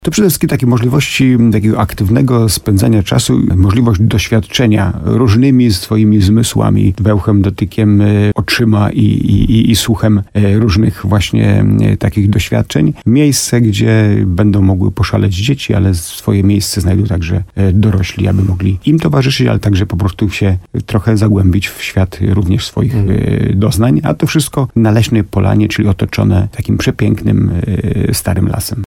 Chodzi o atrakcje sensoryczne, strefy odpoczynku, czy plac zabaw – mówił w programie Słowo za Słowo w radiu RDN Nowy Sącz burmistrz Starego Sącza Jacek Lelek.